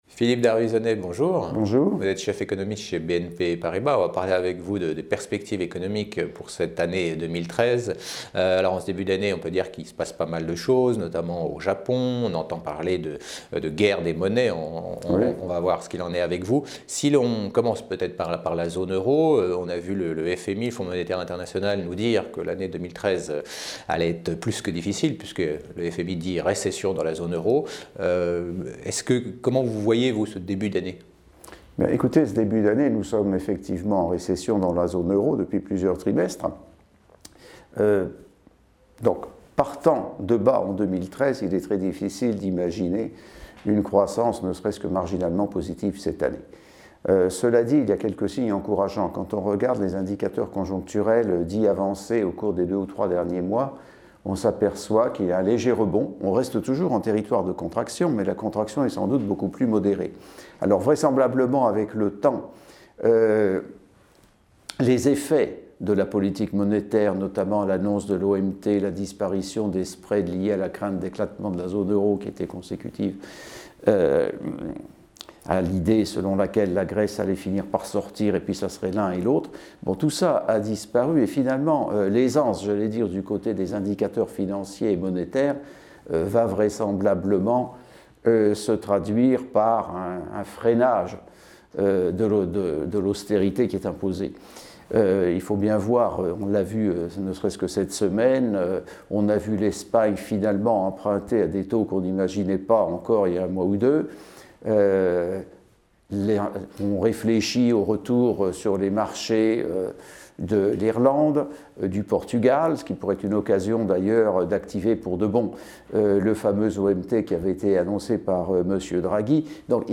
Economie - Perspectives 2013 : Interview